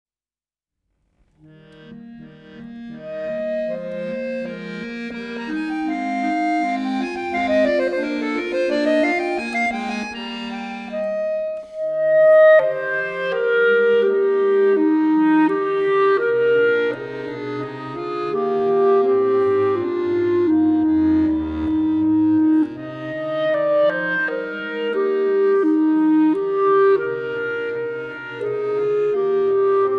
mix popular adn scholarly types of music.
a contemporary duet for clarinet and accordion